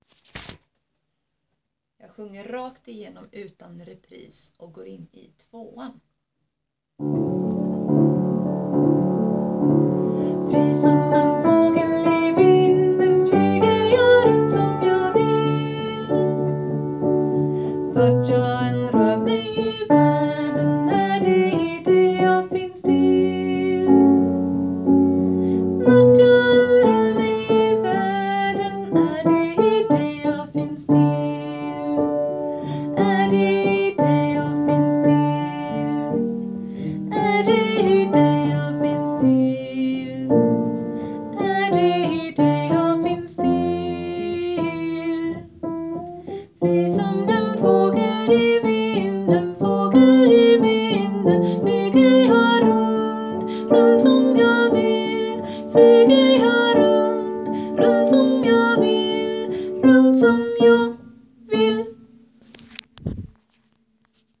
Fri som en fågel bas
fri som bas.wav